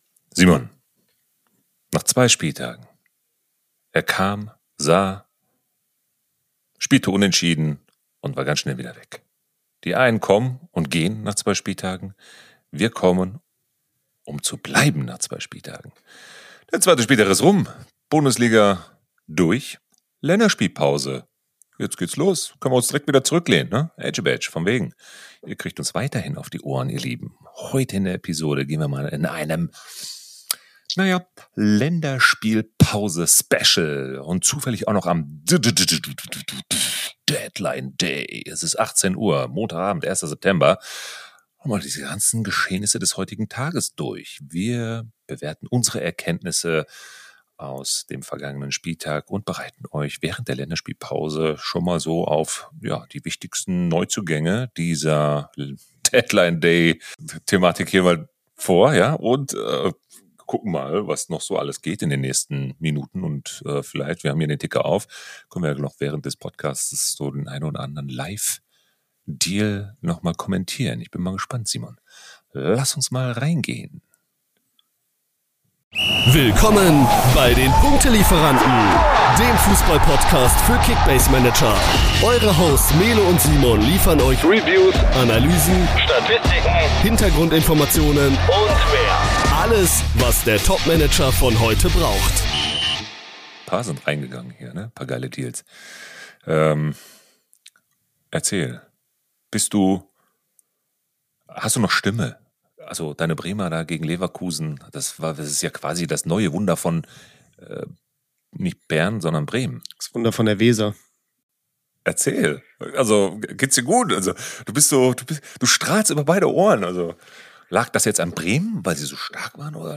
Unsere Hosts: Erlebe die perfekte Mischung aus Humor und Analyse!
Zusammen mit unseren Gästen bieten wir dir eine unterhaltsame und informative Show.